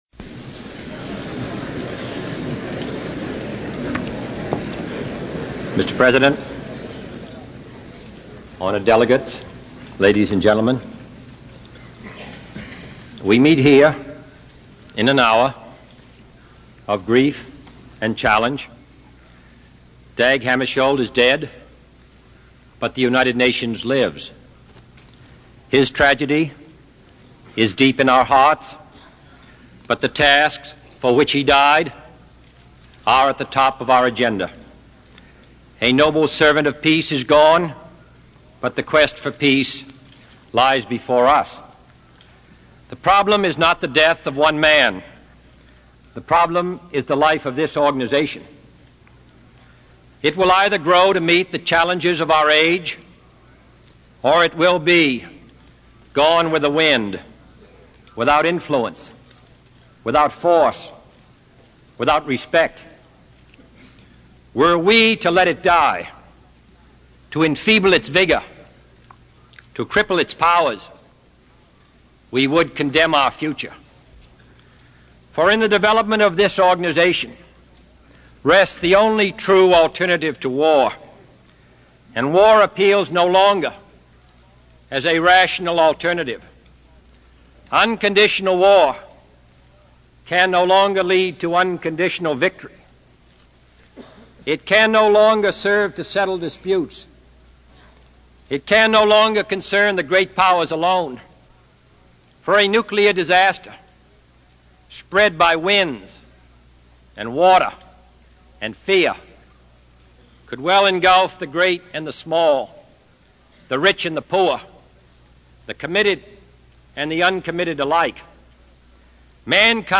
John F Kennedy Address to United Nations 1